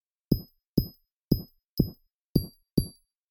This Gemstone Sound resource pack adds a custom audio when breaking glass.
Giving your gemstones a rythmic feel when mining.
gemstone_sounds.mp3